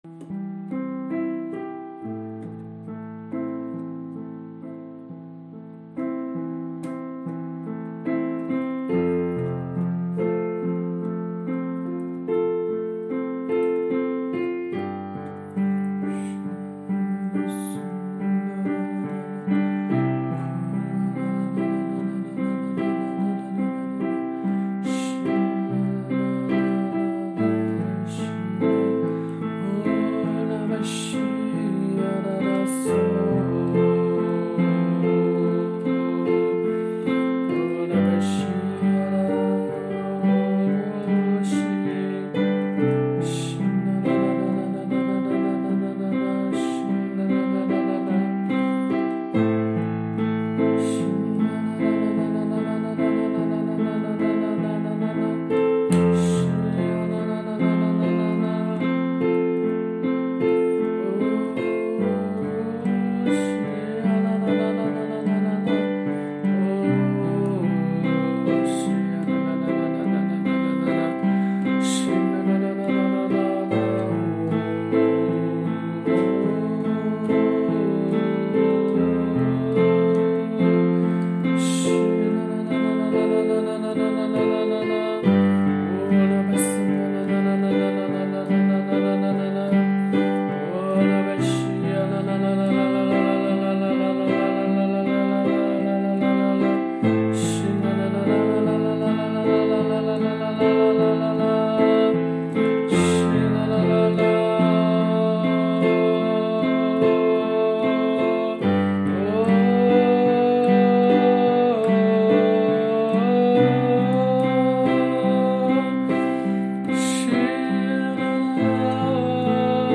HAKA祷告敬拜MP3 启示性祷告： 持续祷告： 祈求神的旨意成就在我们身上，启示性恩膏临到，明白身份，听到呼召，进入命定！